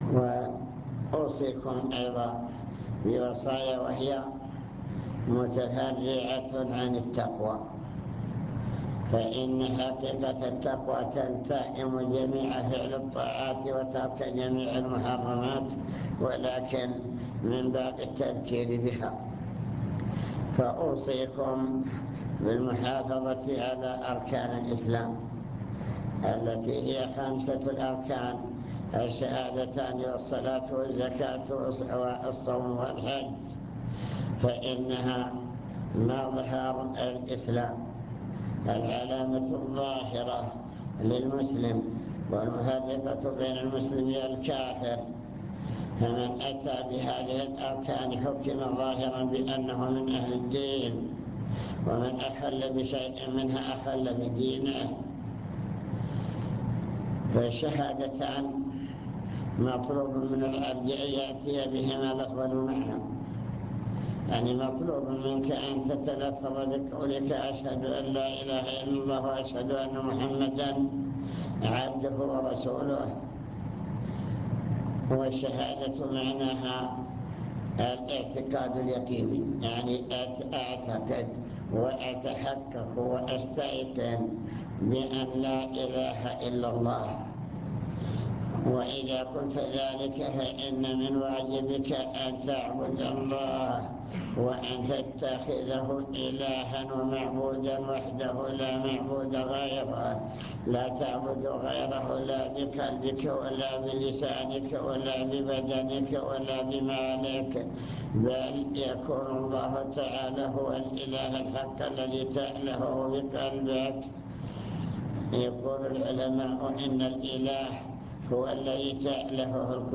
المكتبة الصوتية  تسجيلات - محاضرات ودروس  محاضرة القاعة